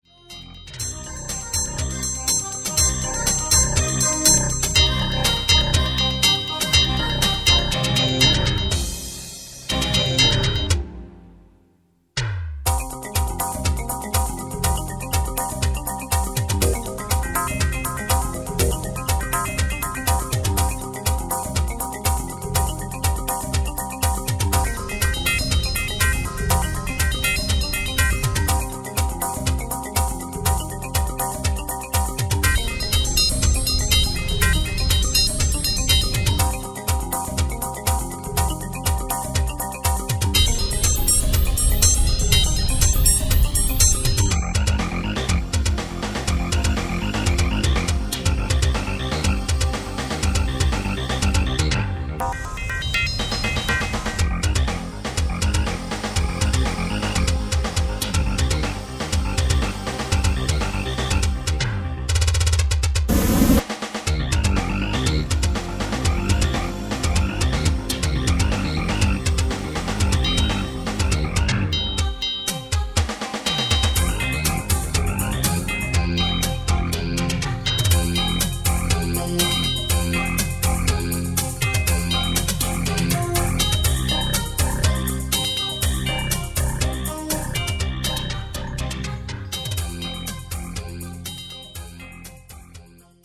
Musique électronique